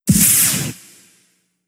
Laser Impact.wav